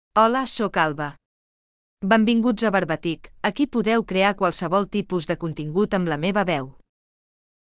Alba — Female Catalan AI voice
Alba is a female AI voice for Catalan.
Voice sample
Listen to Alba's female Catalan voice.
Alba delivers clear pronunciation with authentic Catalan intonation, making your content sound professionally produced.